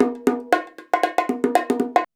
119BONG16.wav